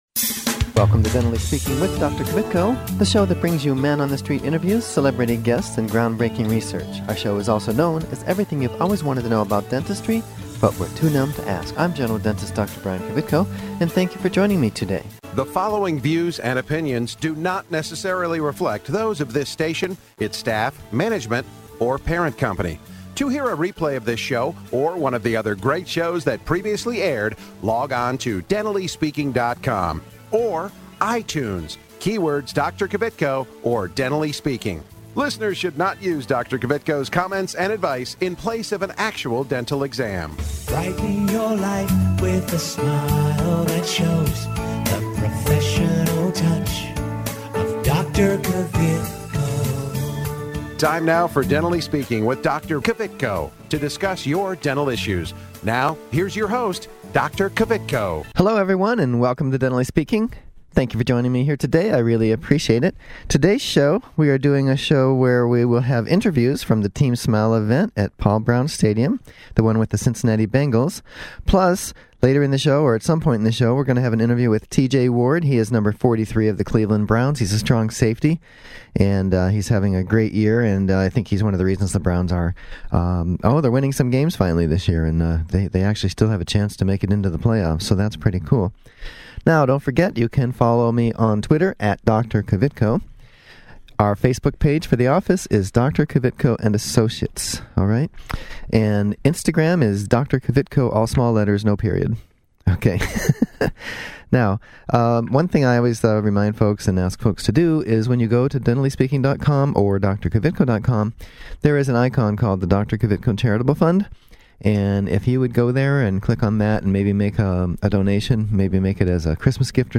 Interviews from the Team Smile event with the Cincinnati Bengals